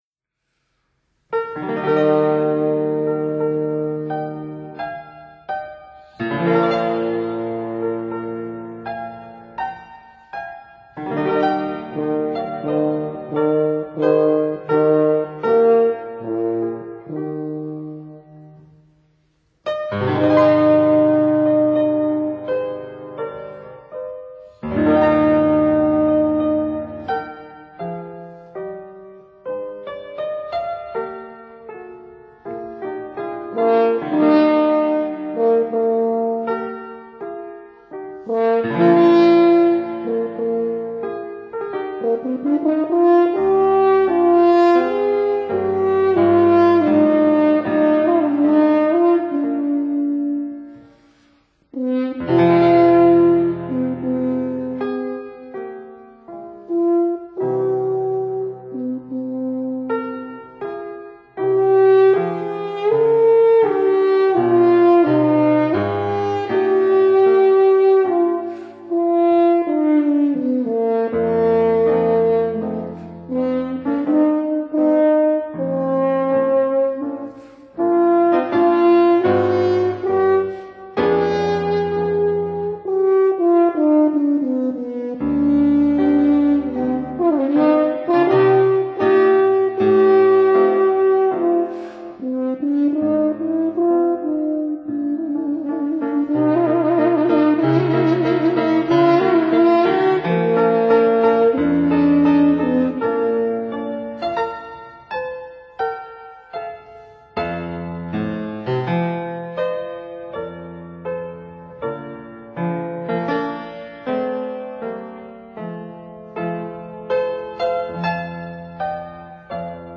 Horns
Fortepiano
(Period Instruments)